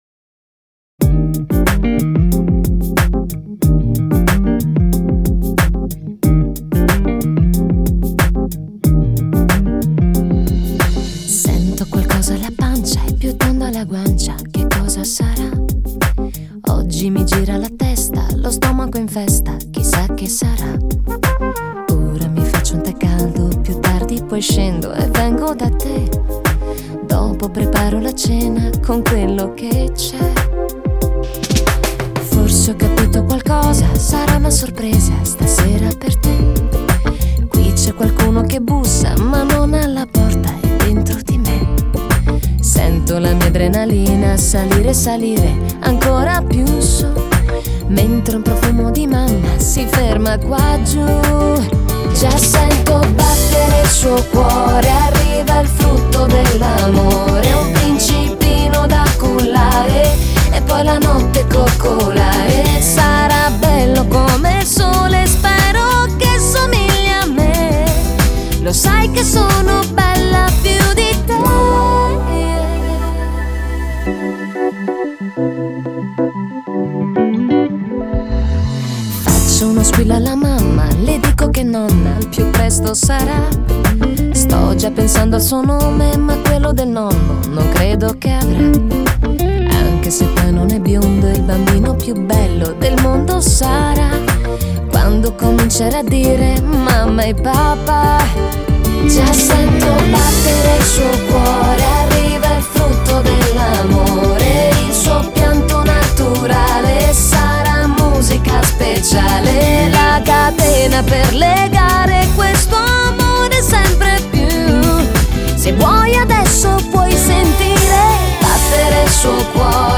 音乐类别：POP